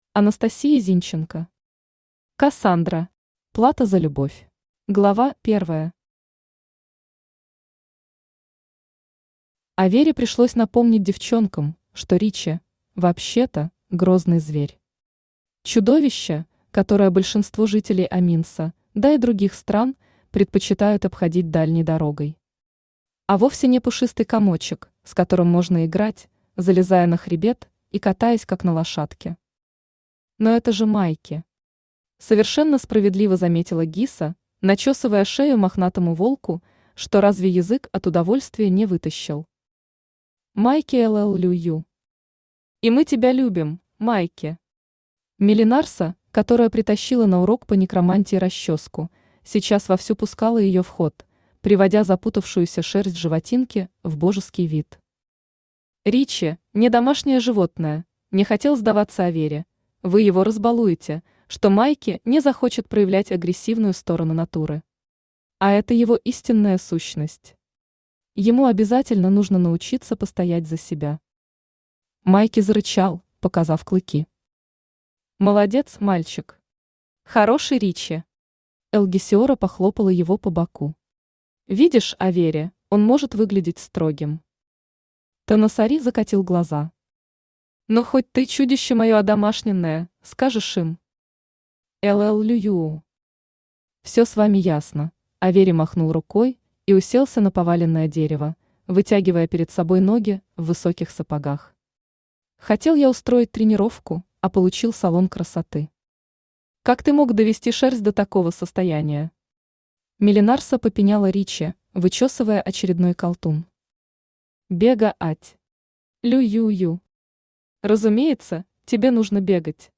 Аудиокнига Кассандра. Плата за любовь | Библиотека аудиокниг
Плата за любовь Автор Анастасия Зинченко Читает аудиокнигу Авточтец ЛитРес.